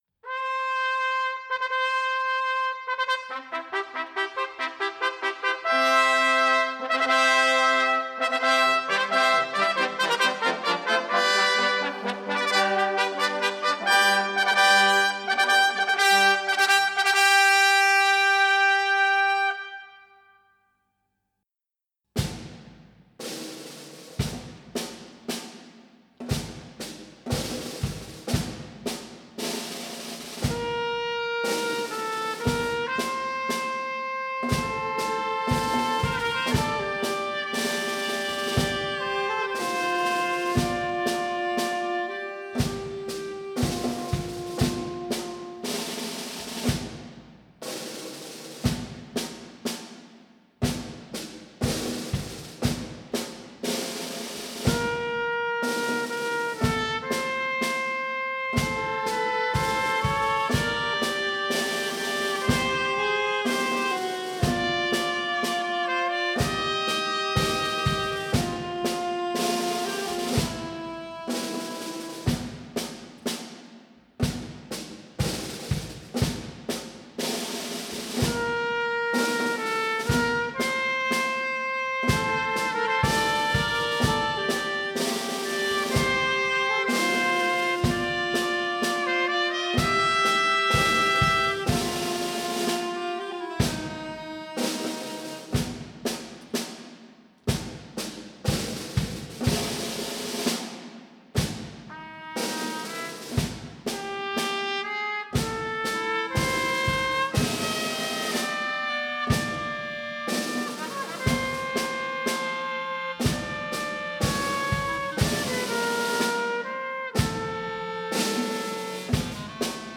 Harmonie